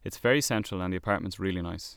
Dublin accent